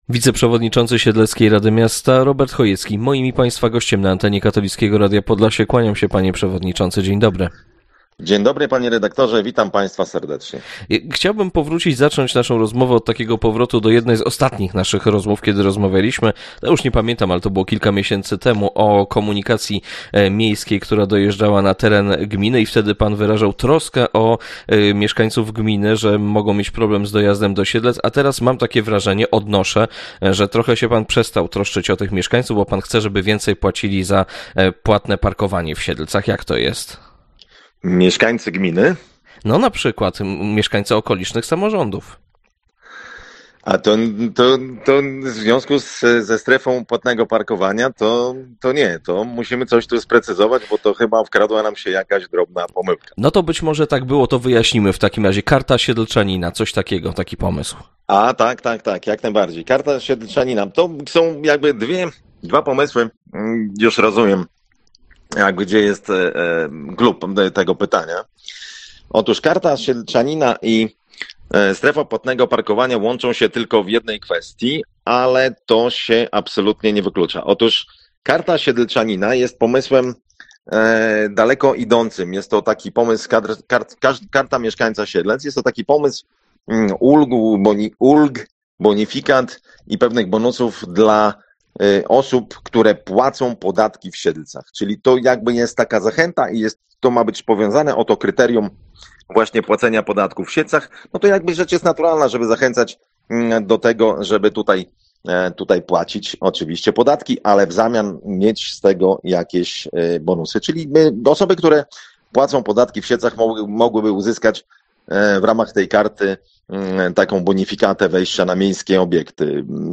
Wiceprzewodniczący siedleckiej Rady Miasta Robert Chojecki w rozmowie z Radiem Podlasie odniósł się do pomysłu rozszerzenia strefy płatnego parkowania w mieście.